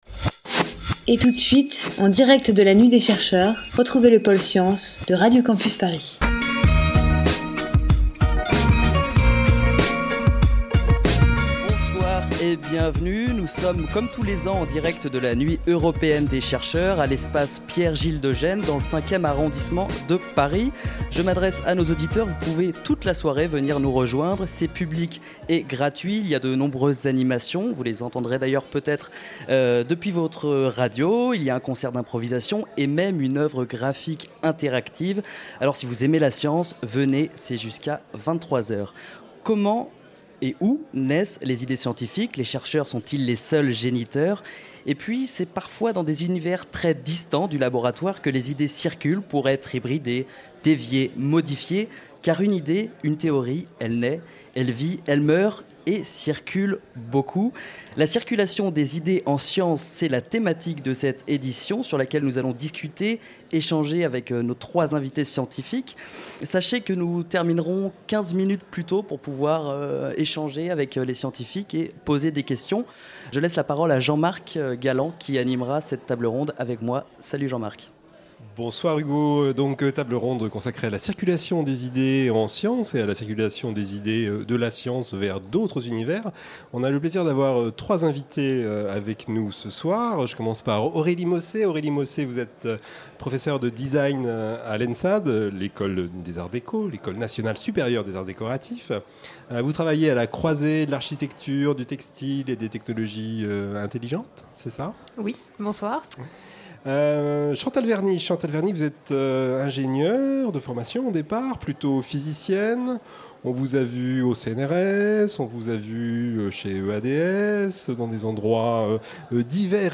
Vendredi 30 septembre, entre 19h et 20h, le Pôle science de Radio Campus sera en direct de l'Espace Pierre-Gilles de Gennes (5ème) à l'occasion de la Nuit européenne des chercheurs.